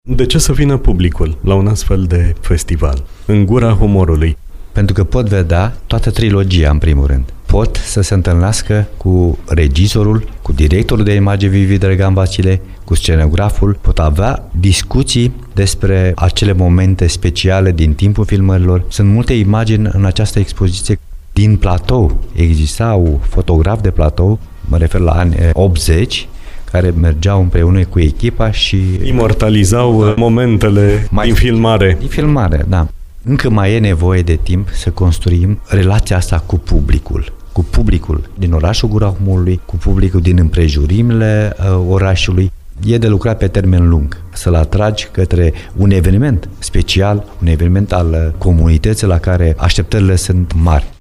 în dialog